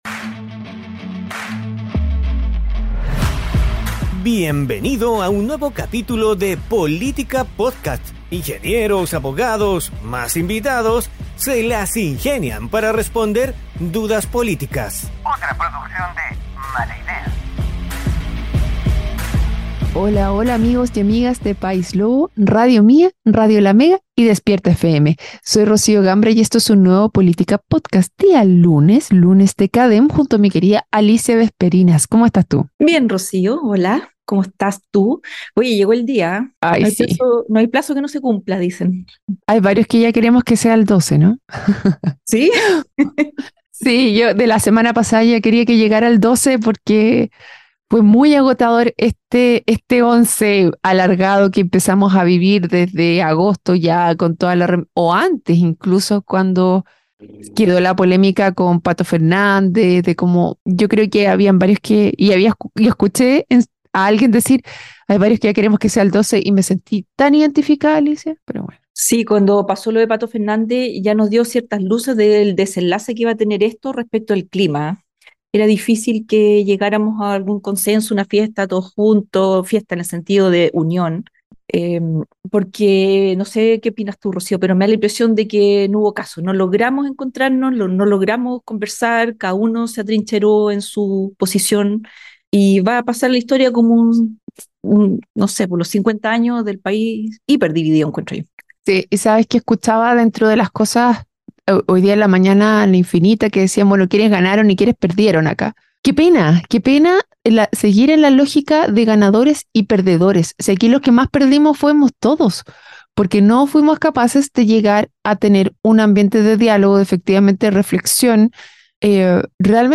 Las conductoras